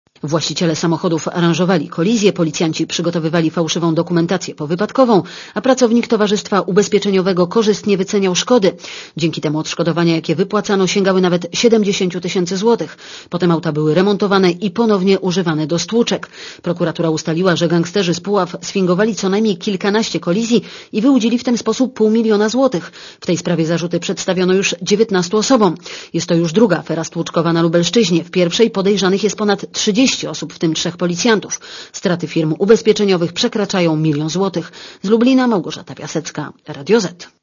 Posłuchaj relacji reporterki Radia Zet (145 KB)